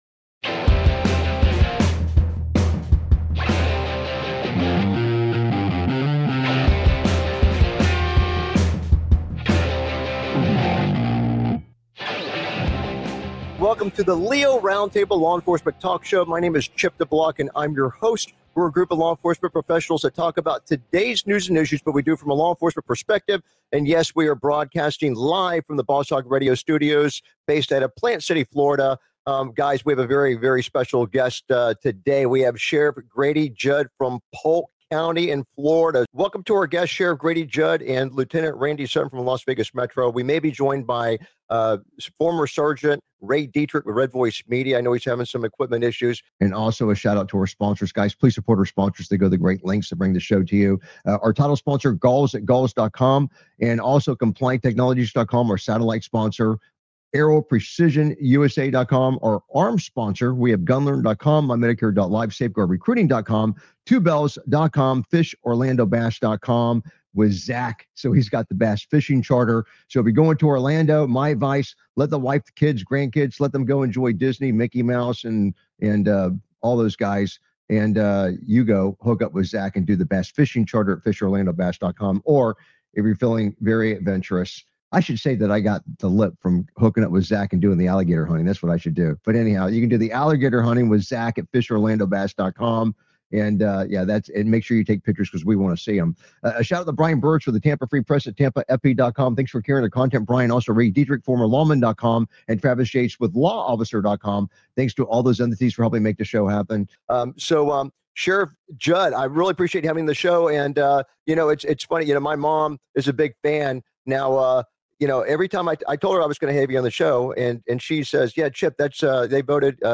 Talk Show Episode
Sheriff Grady Judd talks working with the media. Reality-based training takes center stage for recruits.